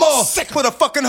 Index of /m8-backup/M8/Samples/breaks/breakcore/evenmorebreaks/v0x
sickloop.wav